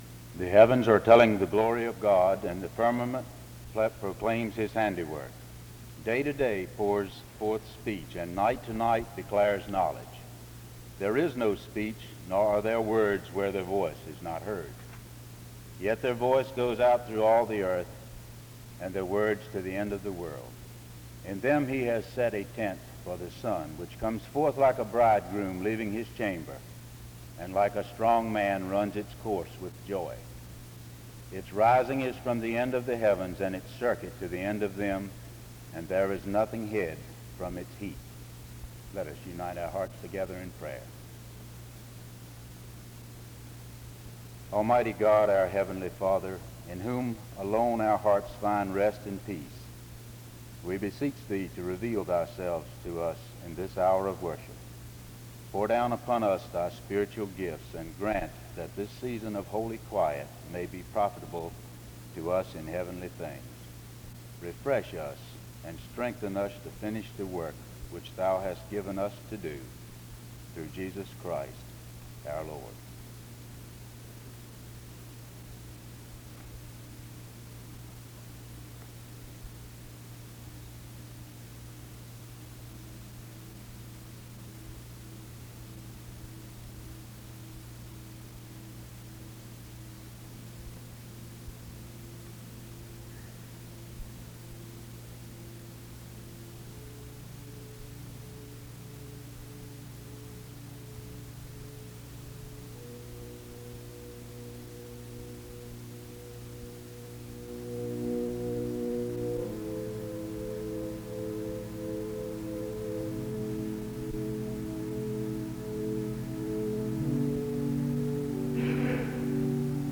SEBTS_Chapel_Student_Coordinating_Council_1966-11-10.wav